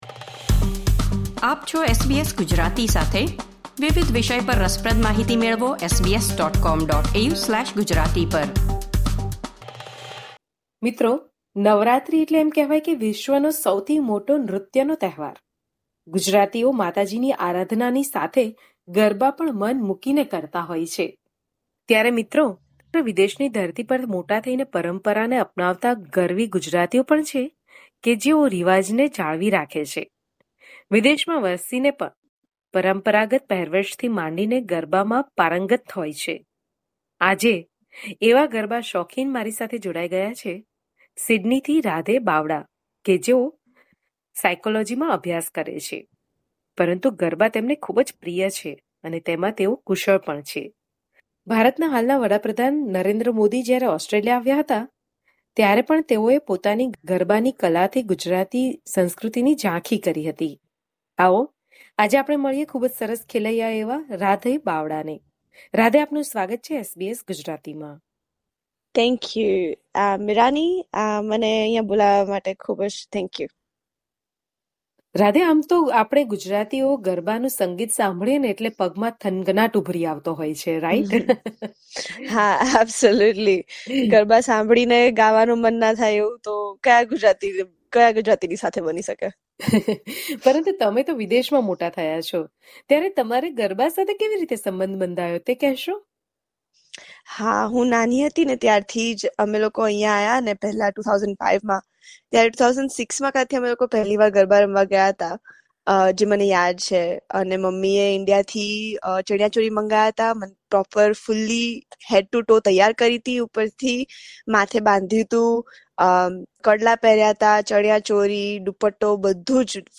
અહેવાલમાં સંવાદો છે